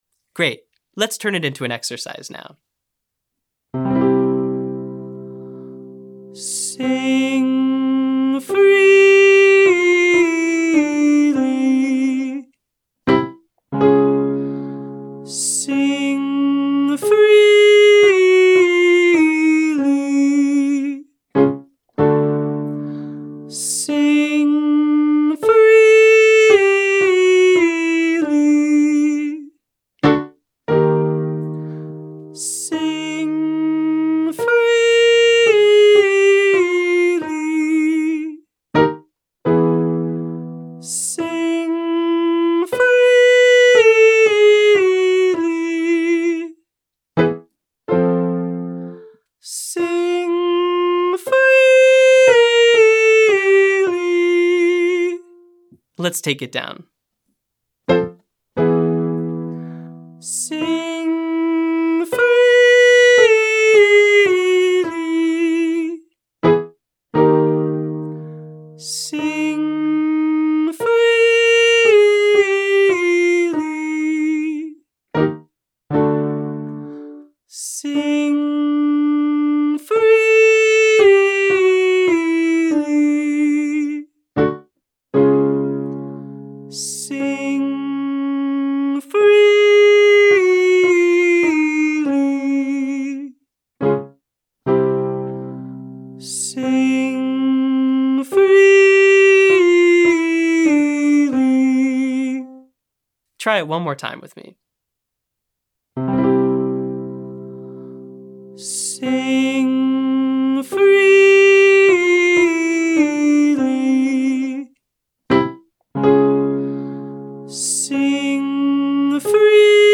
Cord Compression - Online Singing Lesson
• Sing Freely (1-54321) with an NG sound to feel proper resonance in head voice.